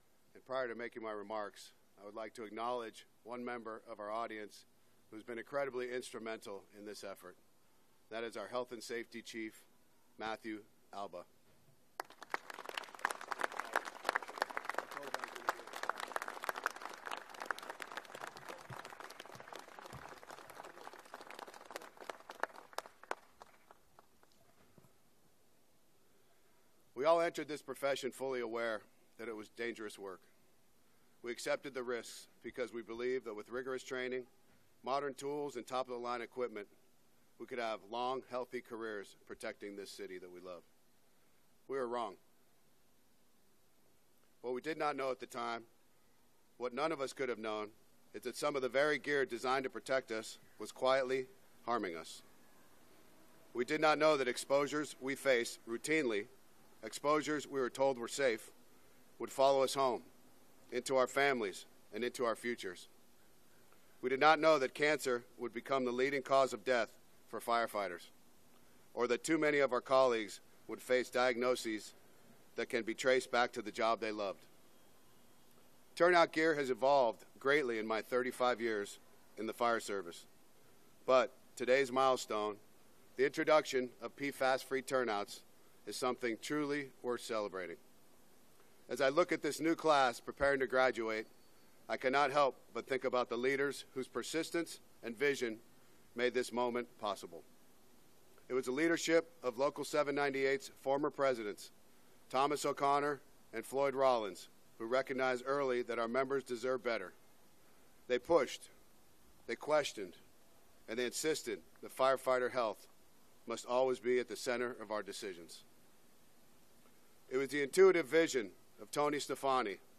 Mayor's Press Conference Audio